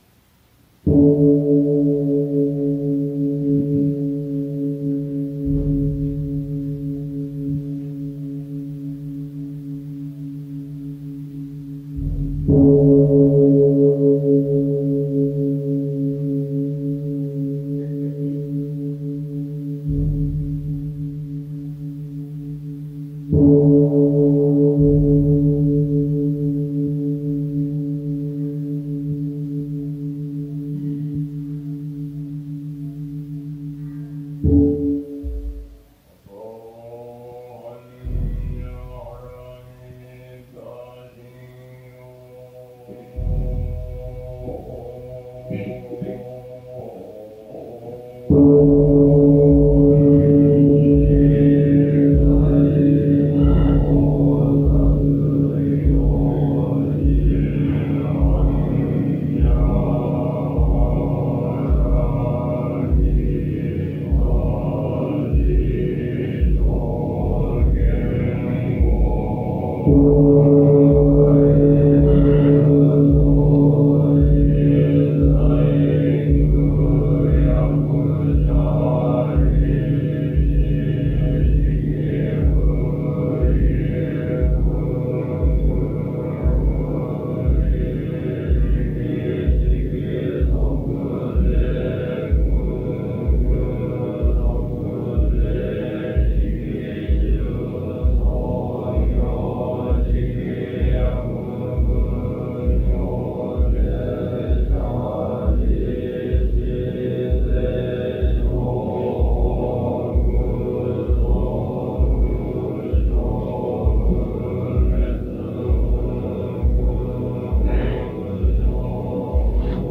Recorded in Fukui Prefecture, Japan